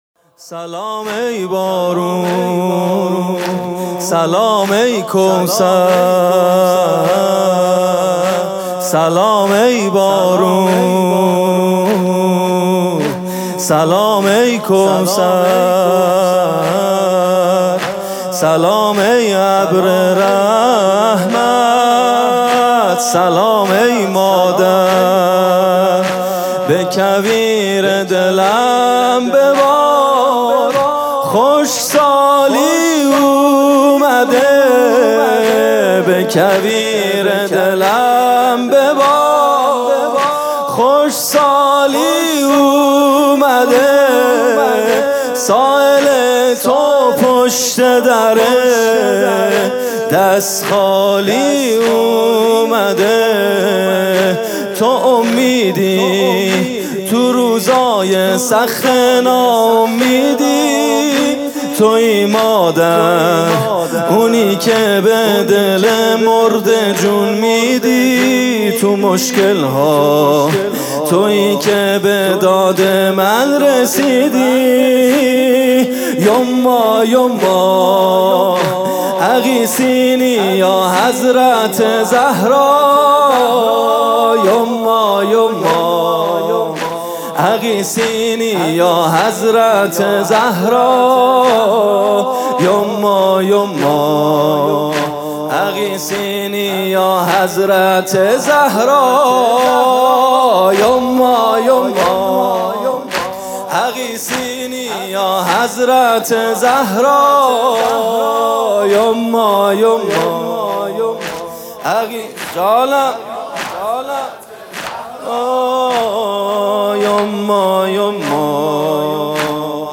music-icon زمینه: به کویر دلم ببار خشک سالی اومده